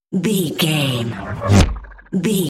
Sci fi pass by insect wings fast
Sound Effects
Fast
futuristic
pass by